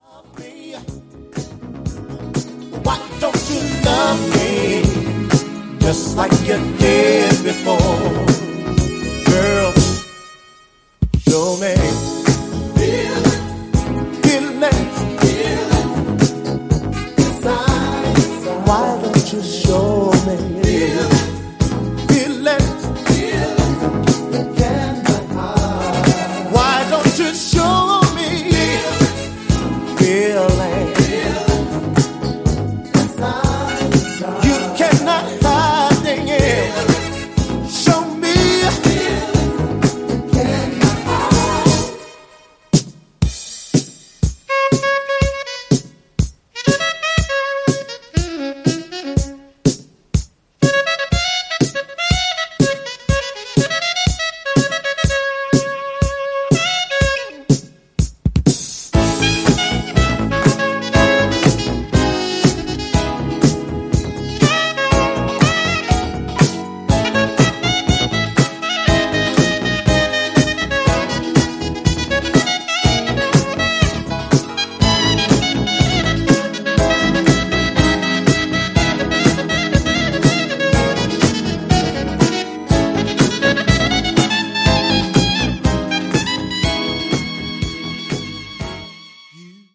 a San Francisco based boogie funk band
saxophone, bass, and guitar